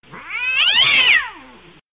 cat02.mp3